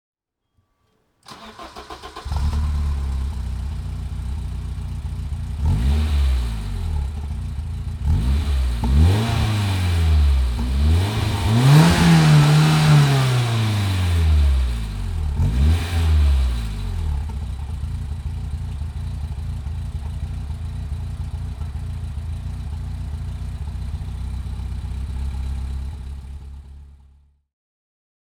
BMW M3 Cabriolet (1990) - Starten und Leerlauf
BMW_M3_Cabrio_1990.mp3